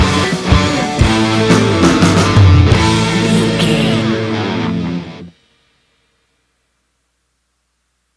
Ionian/Major
A♭
hard rock
heavy rock
distortion
instrumentals